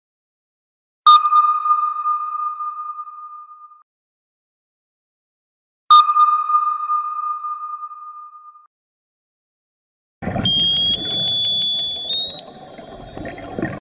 What sonar sound like! sound effects free download